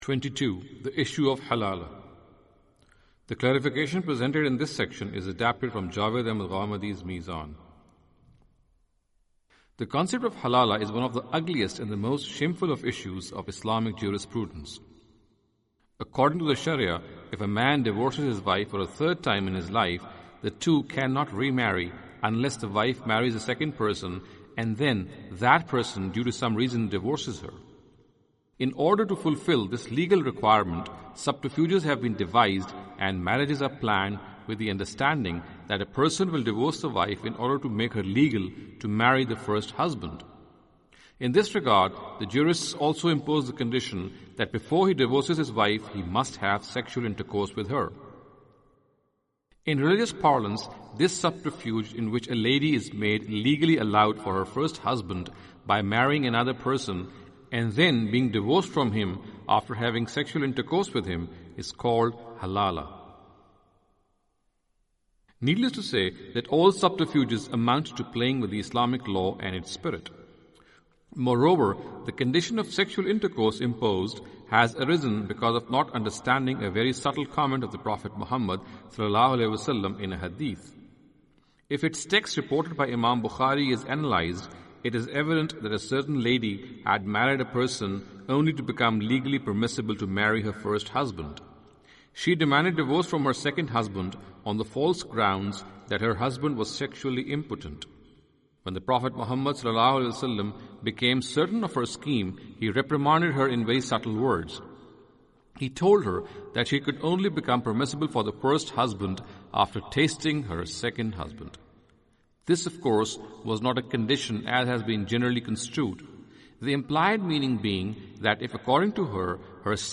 Audio book of English translation of Javed Ahmad Ghamidi's book "Islam and Women".